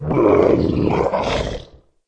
boss die.mp3